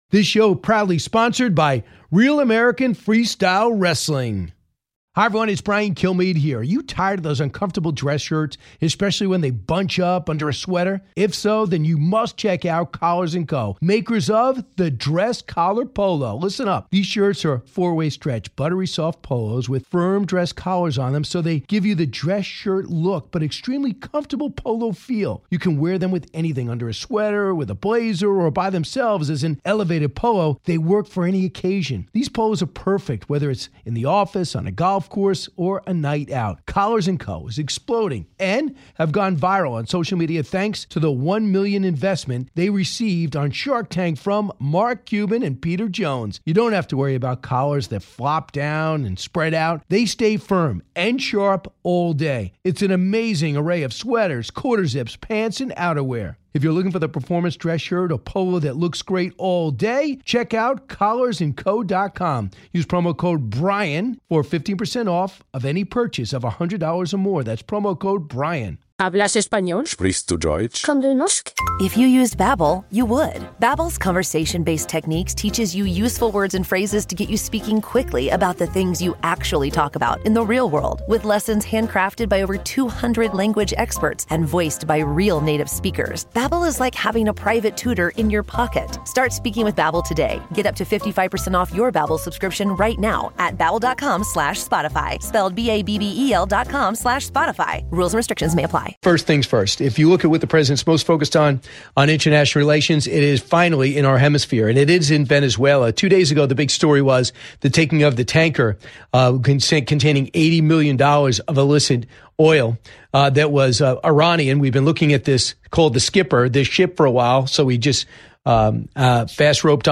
The Truth Network Radio